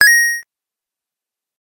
コインを拾う-03【ファミコン風】：レトロな8ビットゲームの効果音 着信音
ピコンという明るい音色です。まるでコインがちょこんと現れるかのような効果音が鳴ります。このシンプルな音色は、8ビットゲームの特徴であり、懐かしさと楽しさを同時に感じさせてくれます。